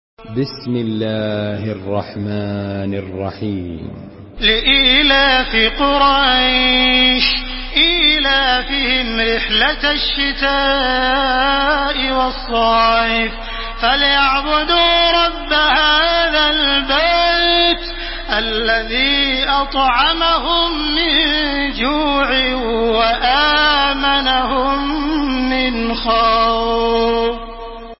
سورة قريش MP3 بصوت تراويح الحرم المكي 1431 برواية حفص
تحميل سورة قريش بصوت تراويح الحرم المكي 1431